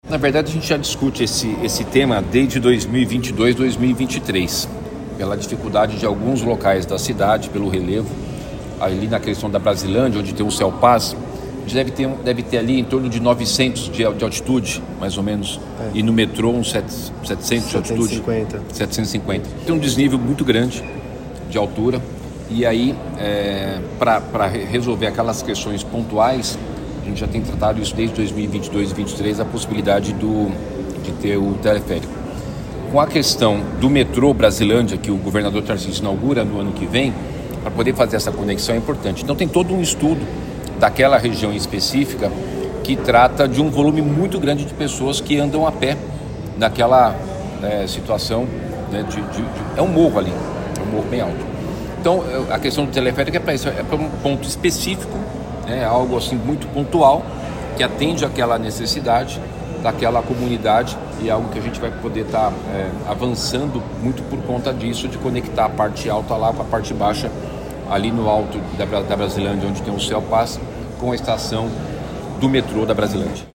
A confirmação foi do prefeito Ricardo Nunes nesta quinta-feira, 27 de março de 2025, em entrevista durante uma agenda.